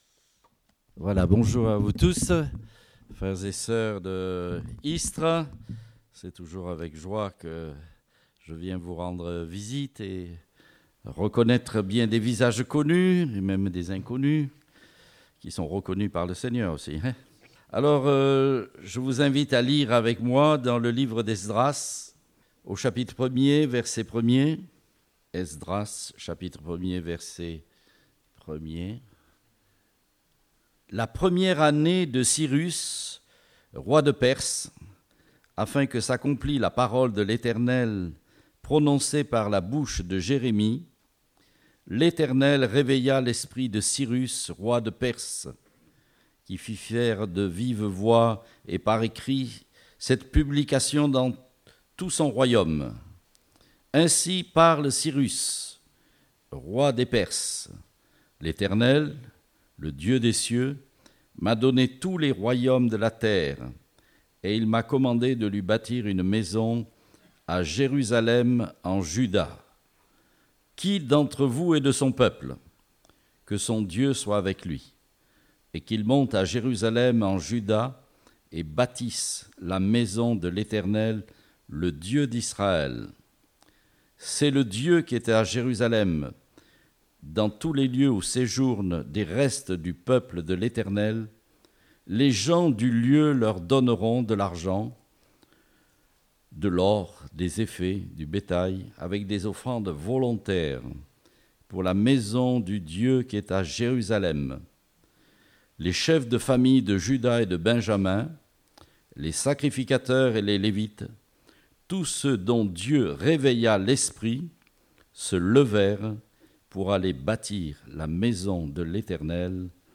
Date : 3 mars 2019 (Culte Dominical)